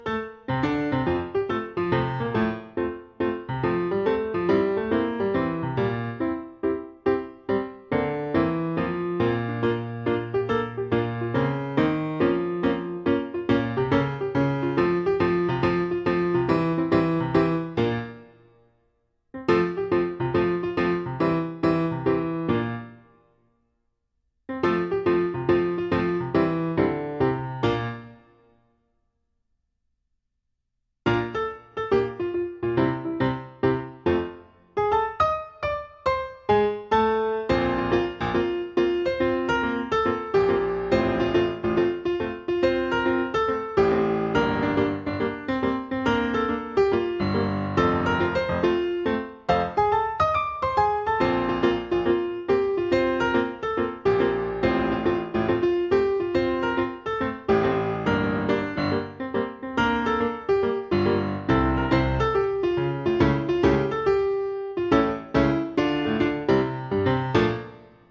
• Background Track: